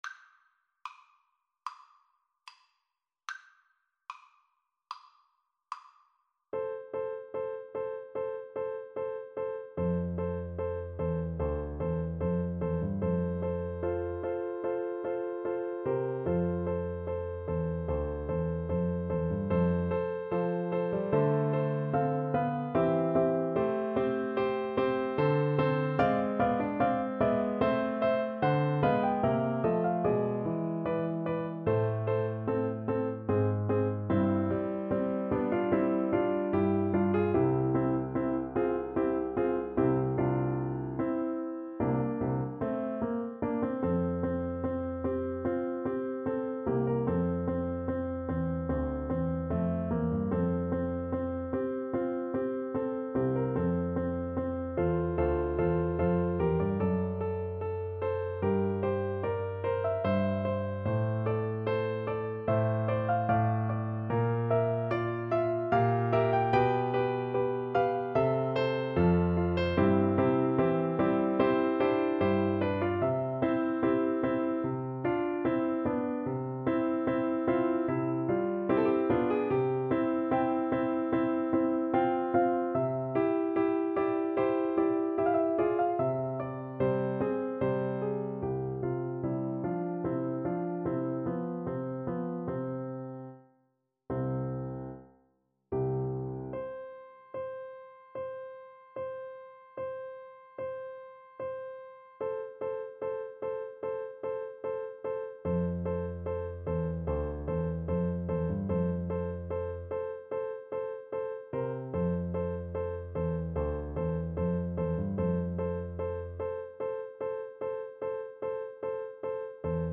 Play (or use space bar on your keyboard) Pause Music Playalong - Piano Accompaniment Playalong Band Accompaniment not yet available transpose reset tempo print settings full screen
G major (Sounding Pitch) A major (Clarinet in Bb) (View more G major Music for Clarinet )
~ = 74 Moderato
4/4 (View more 4/4 Music)
Classical (View more Classical Clarinet Music)